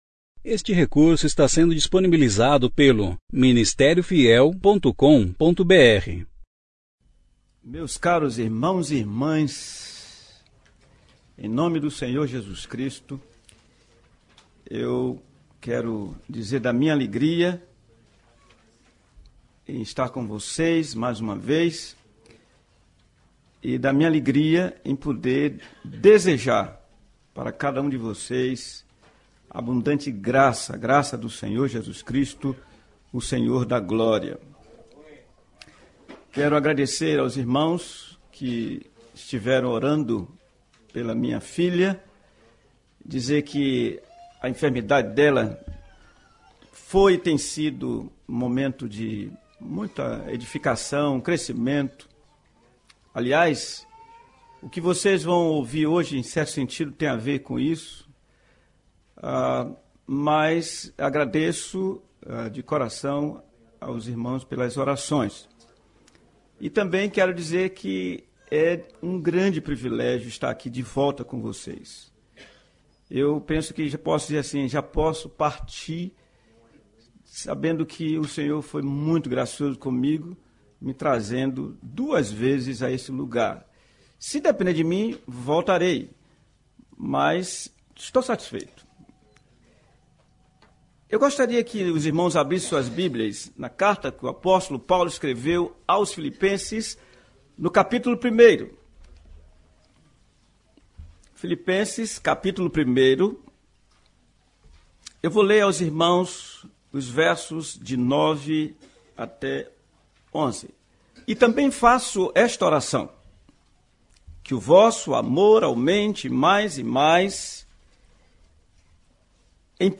1ª Conferência Fiel para Pastores e Líderes - Nampula - Ministério Fiel